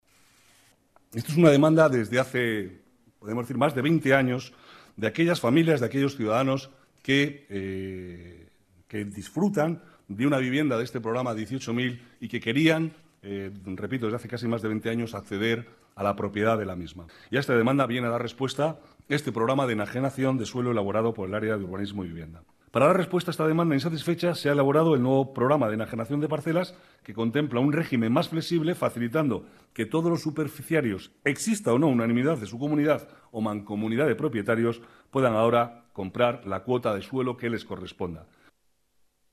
Nueva ventana:Declaraciones vicealcalde, Manuel Cobo: Plan 18.000